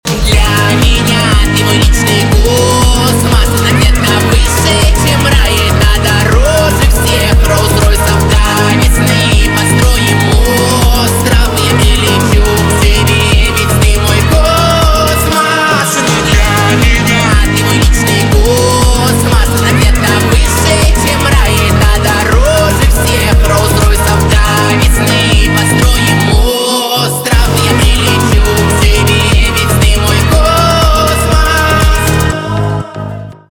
поп
битовые
качающие